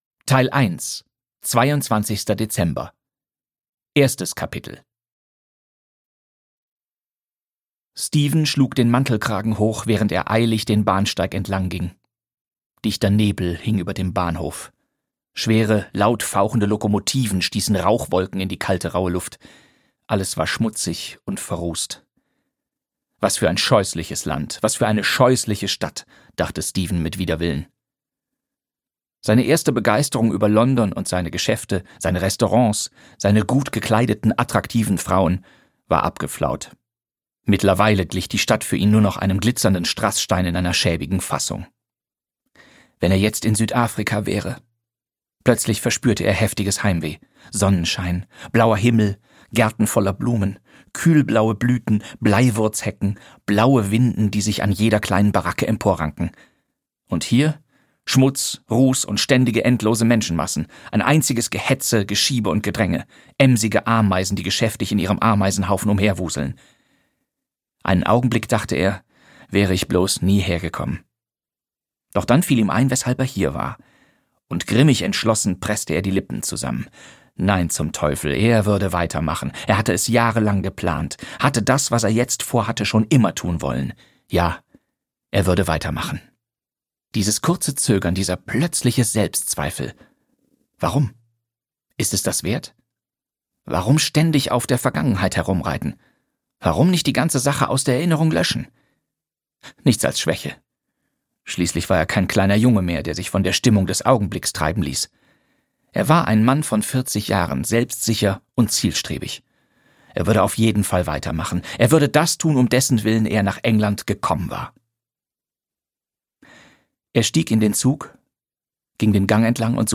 Hercule Poirots Weihnachten (DE) audiokniha
Ukázka z knihy
• InterpretBastian Pastewka